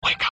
whispering wake up
Category: Sound FX   Right: Both Personal and Commercial